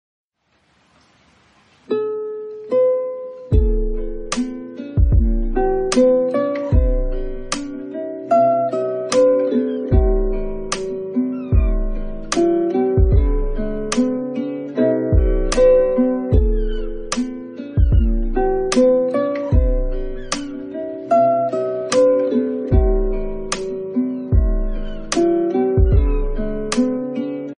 Lofi Chill